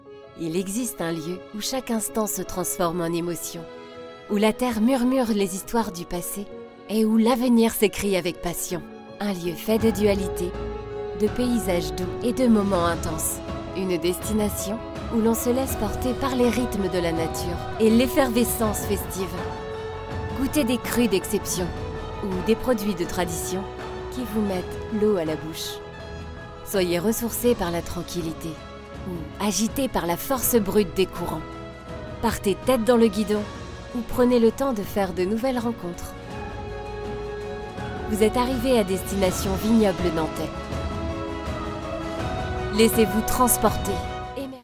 🎙 Voix-off française – Douce, sincère et naturelle
Ma voix est jeune, douce, sincère, avec un léger grain qui apporte chaleur et authenticité à chaque projet.